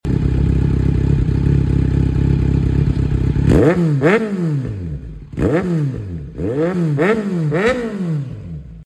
Quick Soundcheck of this Kawasaki sound effects free download